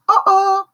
ICQ-Uh-Oh.wav